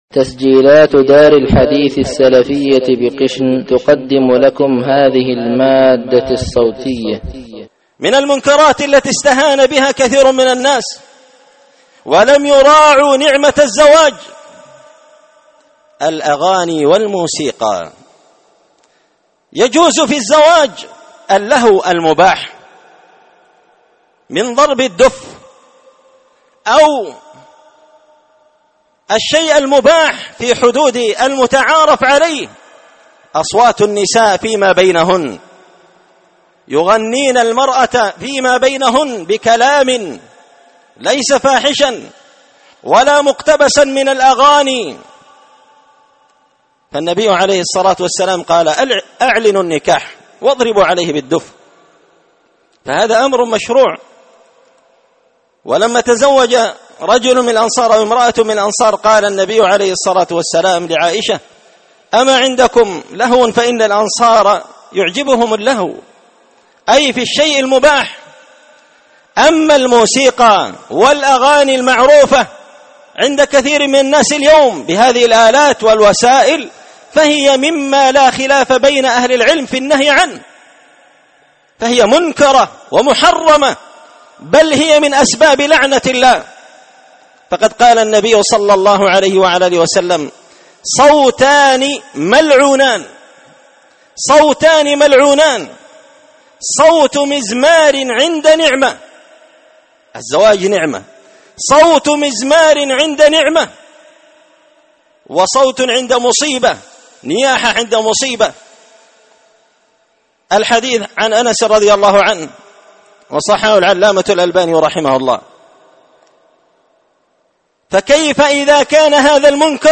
خطبة جمعة بعنوان – تذكير الناس بخطر الاستهانة بمنكرات الأعراس
دار الحديث بمسجد الفرقان ـ قشن ـ المهرة ـ اليمن